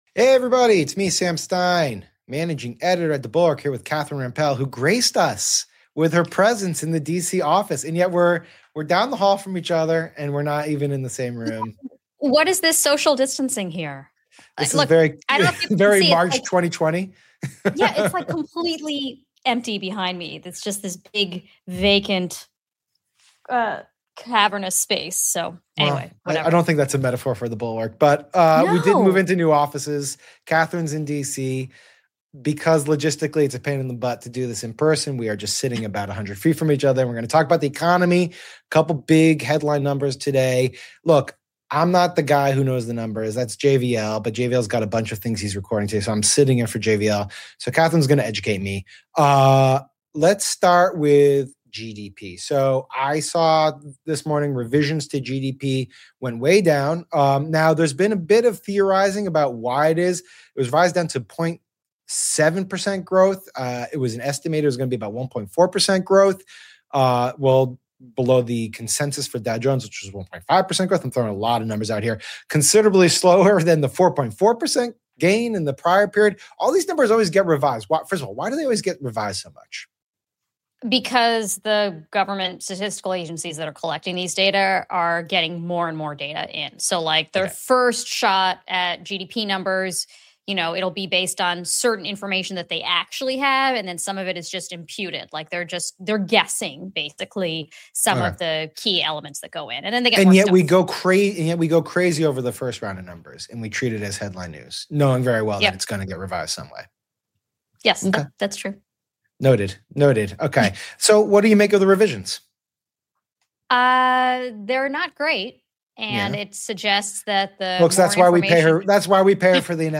Catherine Rampell and Sam Stein are going live to talk about the revised job numbers and other economic news.